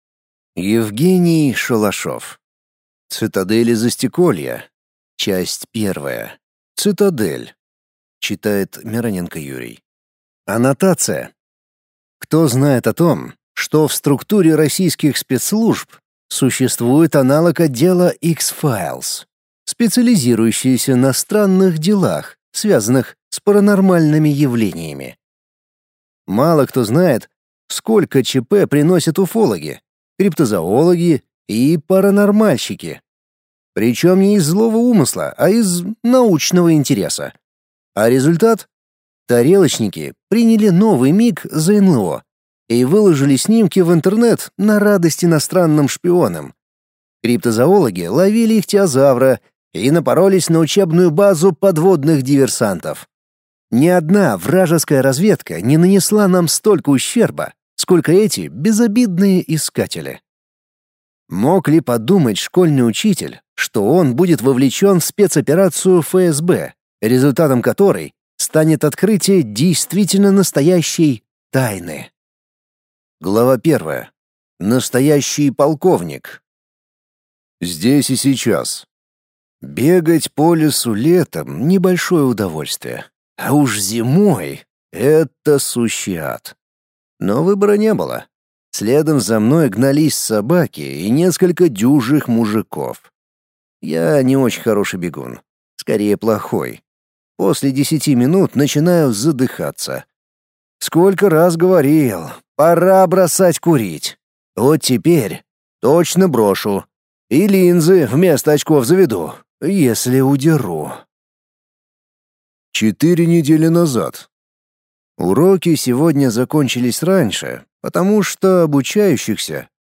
Аудиокнига Цитадели | Библиотека аудиокниг